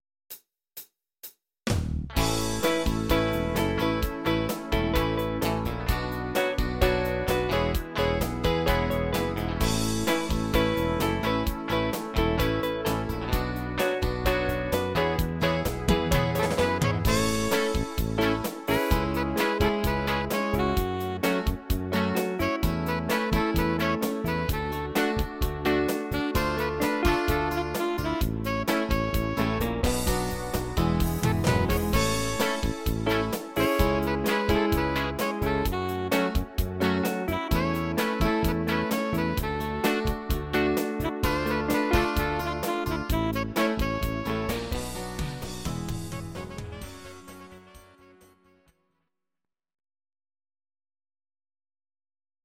Audio Recordings based on Midi-files
Pop, German, 2000s